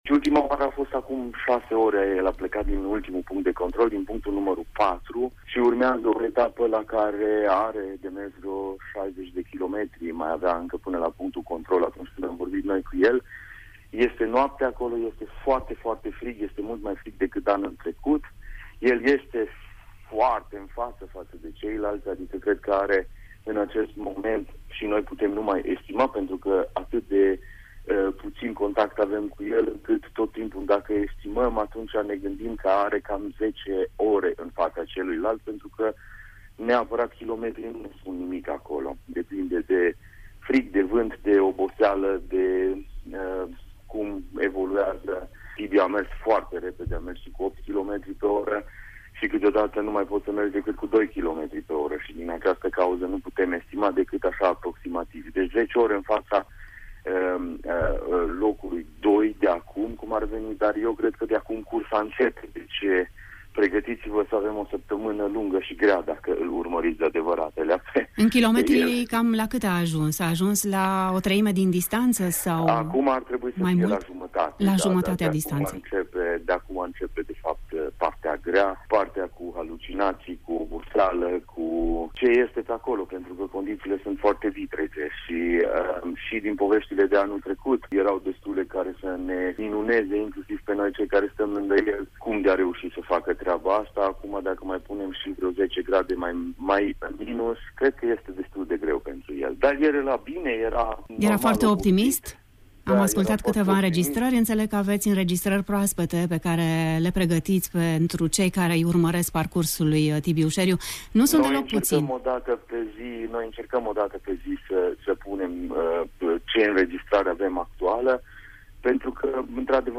In interviul de mai jos